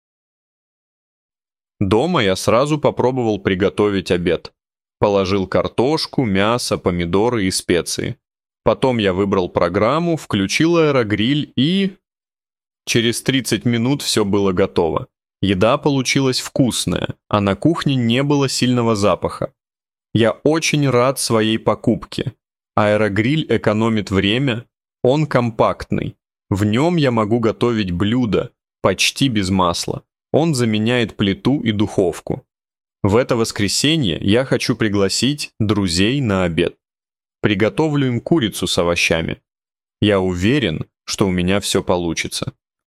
Écoutez une histoire authentique en russe avec traduction française et prononciation claire pour progresser rapidement.